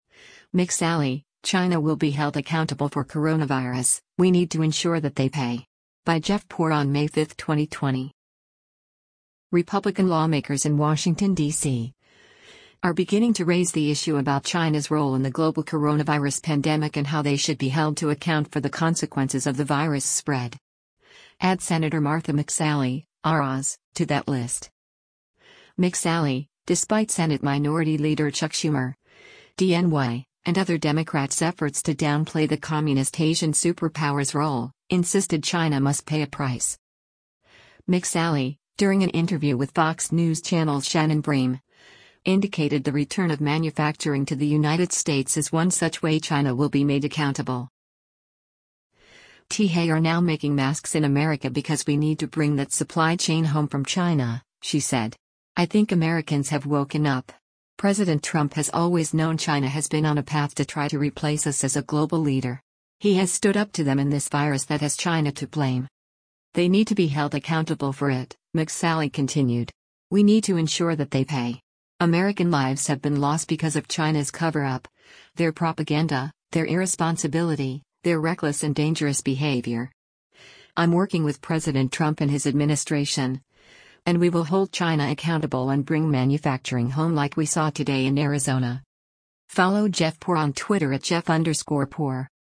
McSally, during an interview with Fox News Channel’s Shannon Bream, indicated the return of manufacturing to the United States is one such way China will be made accountable.